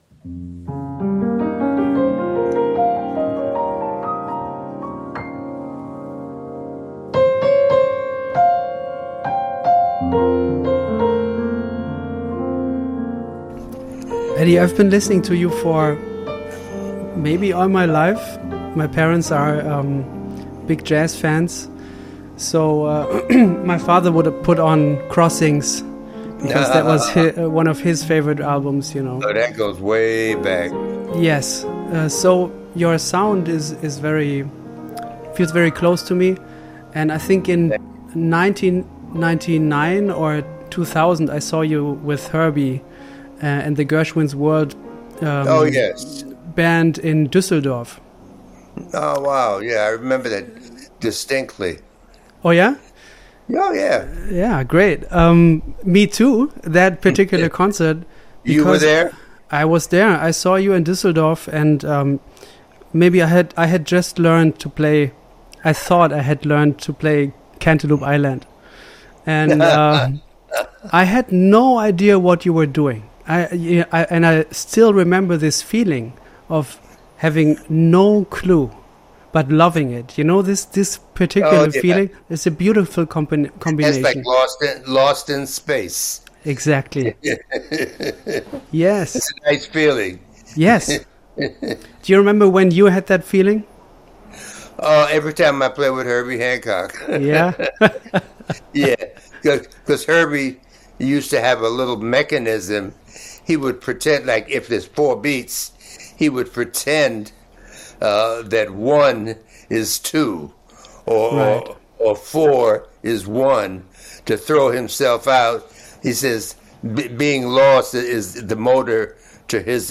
A series of interviews